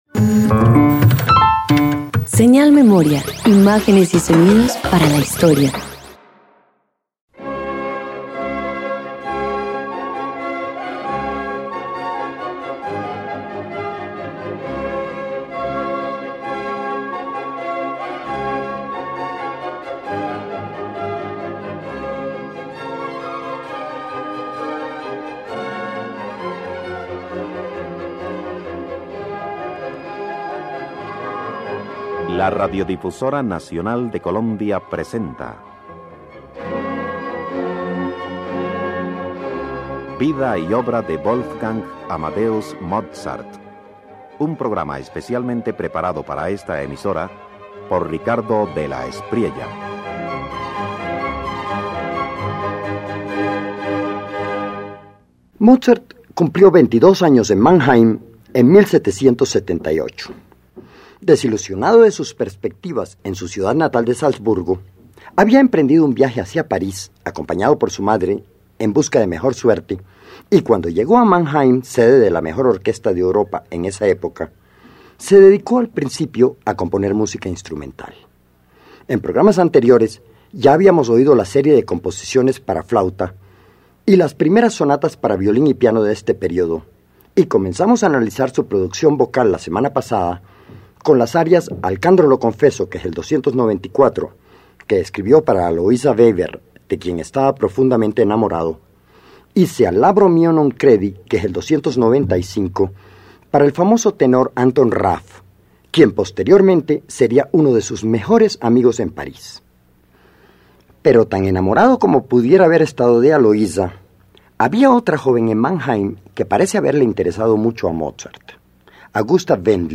Con apenas 22 años, Mozart alterna entre el amor y la creación al componer arias y canciones inspiradas por Augusta Wendling, donde la voz femenina y la orquesta dialogan con delicadeza, preludiando su madurez emocional y musical.
142 Sonatas para piano y violín de Mannheim Parte I_1.mp3